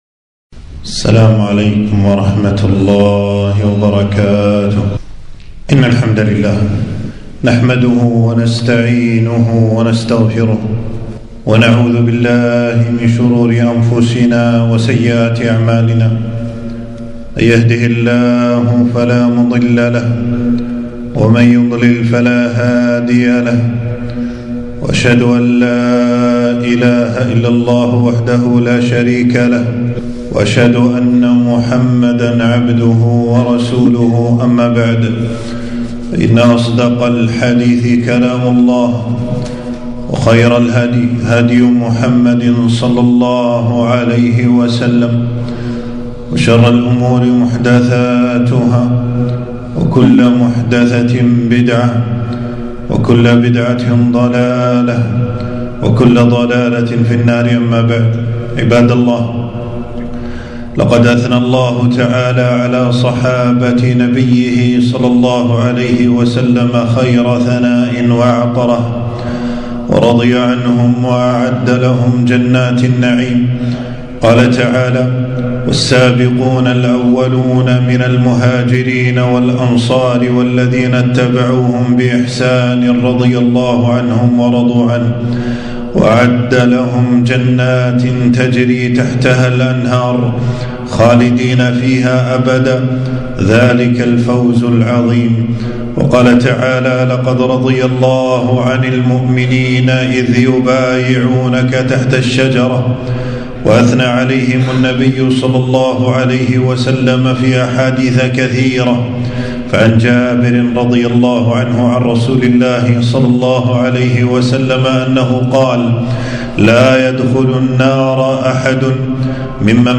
خطبة - فضل الصحابة رضي الله عنهم